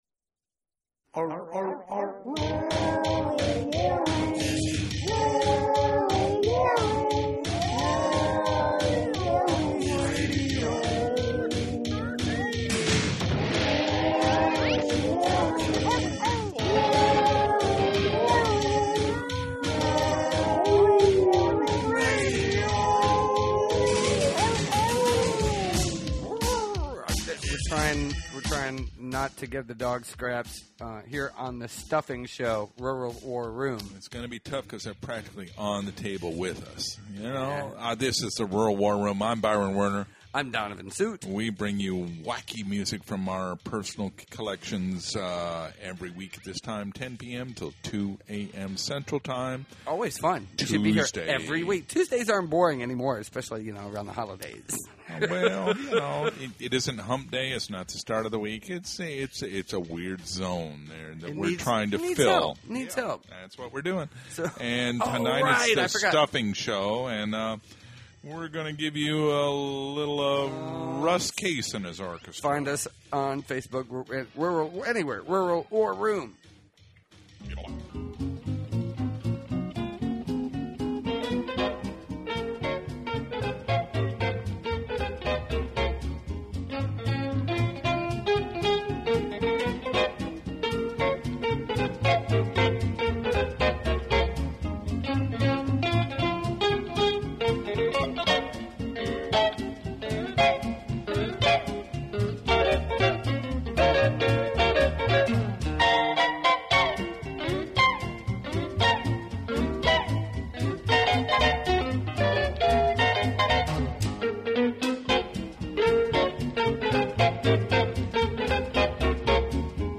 100,000 Watt Radio Broadcast